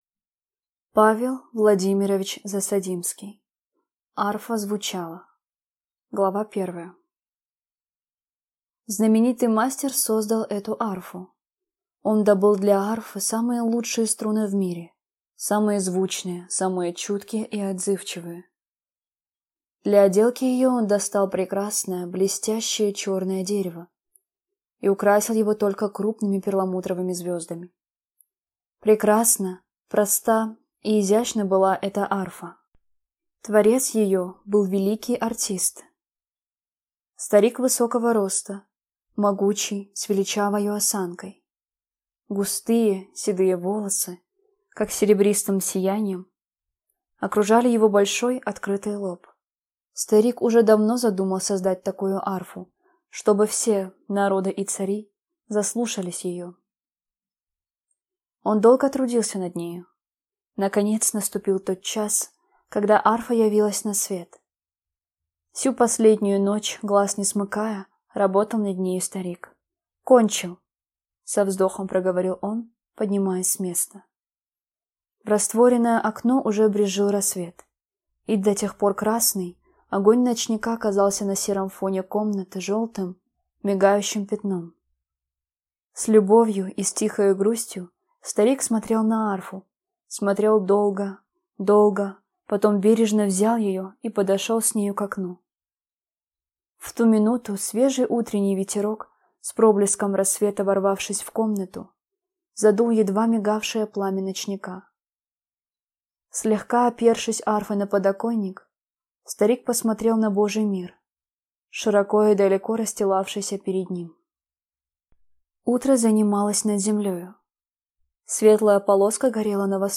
Аудиокнига Арфа звучала | Библиотека аудиокниг